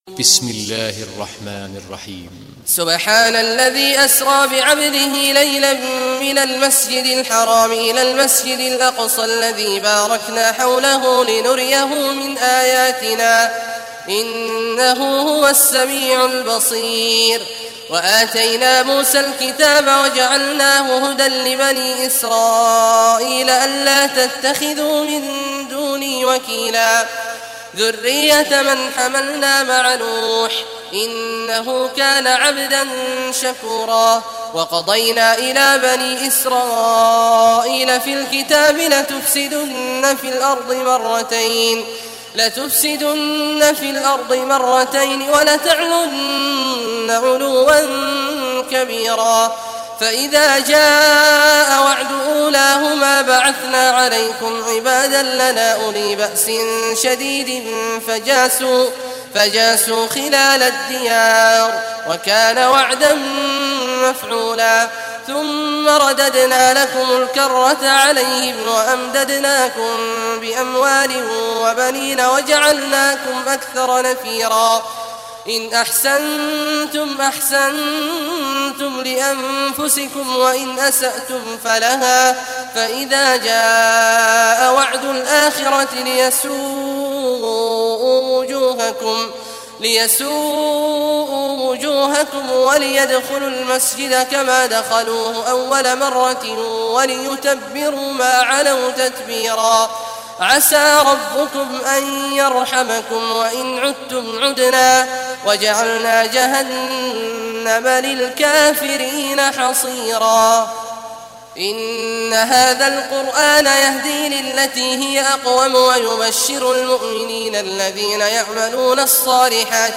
Surah Al-Isra Recitation by Sheikh Awad al Juhany
Surah Al-Isra, listen or play online mp3 tilawat / recitation in Arabic in the beautiful voice of Sheikh Abdullah Awad al Juhany.